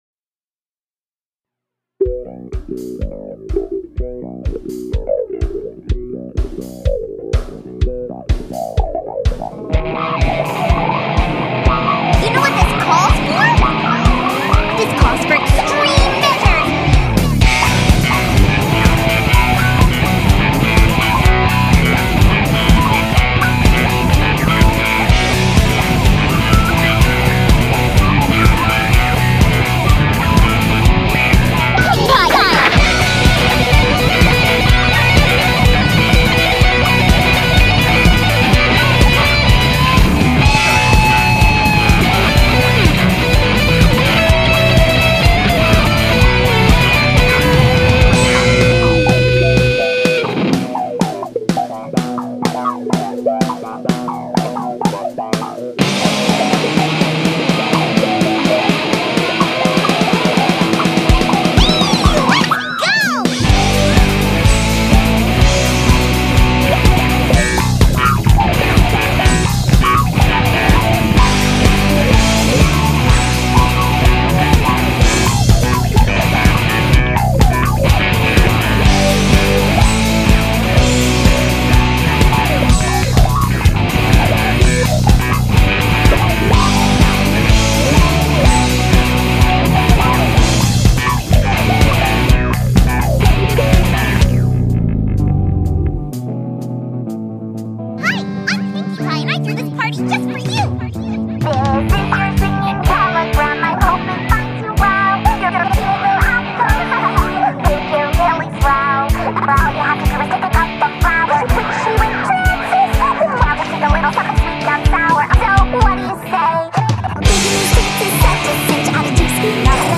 I got this one pedal that made my bass sound all funky.